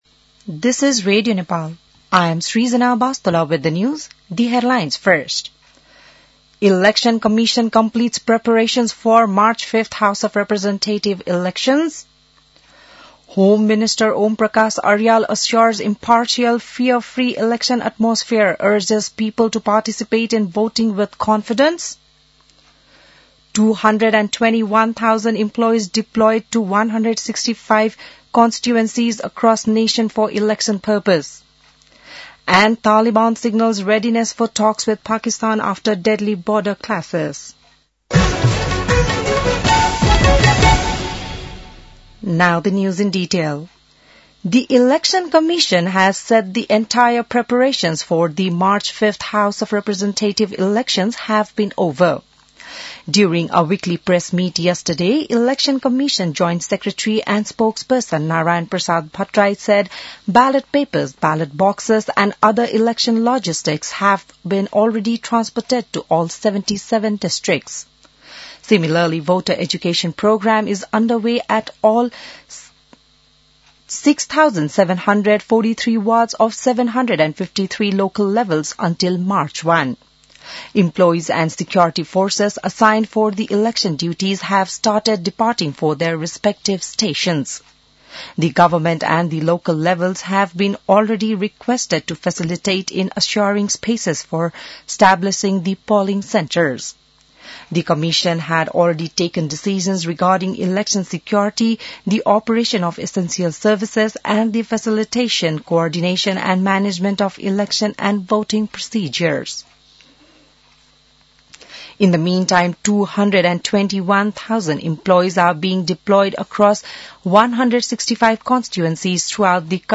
An online outlet of Nepal's national radio broadcaster
बिहान ८ बजेको अङ्ग्रेजी समाचार : १६ फागुन , २०८२